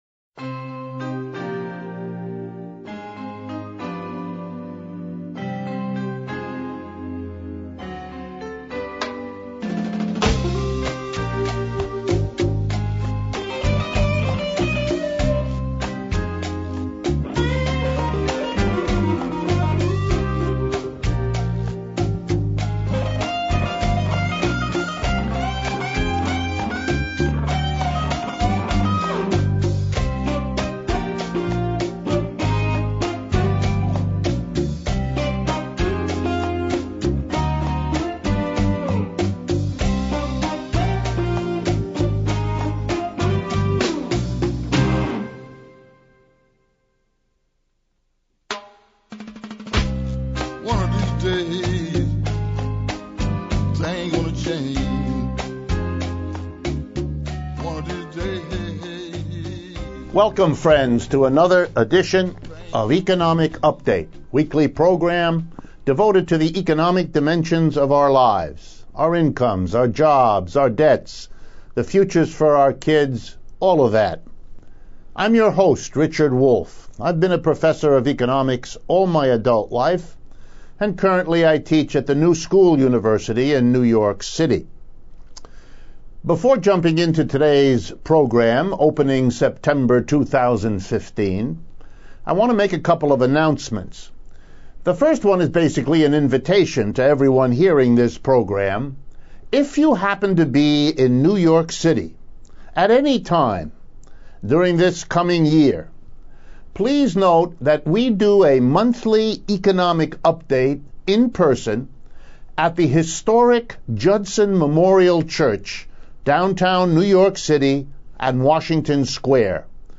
Sept 7, 2015 This week's updates cover extreme poverty, workers' victory over tech giants, NFL concussions, Trump's economics analyzed and Oakland for worker coops. Prof Wolff responds to listeners questions on workers who lost out from 2007-2014 and on the injustice of state and local taxes. We close with major discussions of last week's stock market gyrations and the resurgence of socialism.